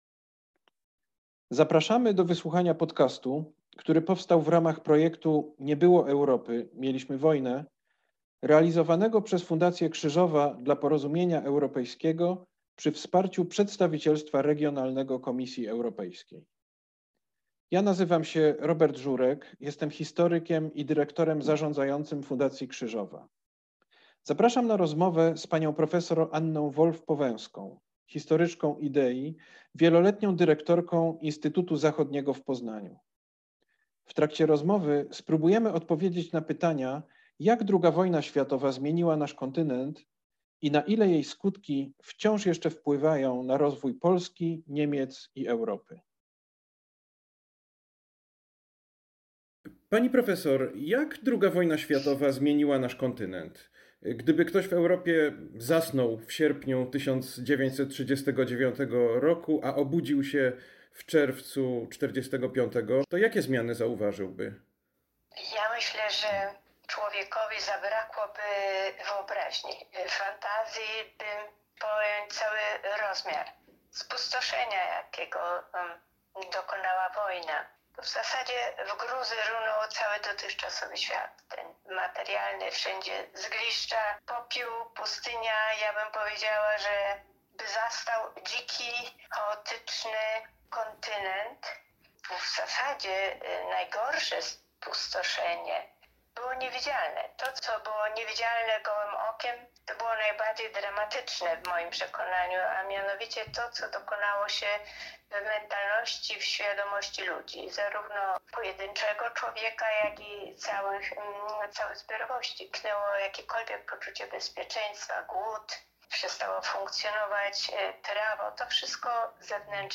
Rozmowa z ekspertem